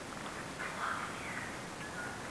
This EVP was recorded at the Ben Lomond Hotel on a tour we gave for a Television show that will be aired on the Discovery Channel. we were just getting started and were walking into the tunnels of the hotel, I opened up the door to the tunnels and you hear this. At the end of the clip you can hear the Night Shot camera being turned on.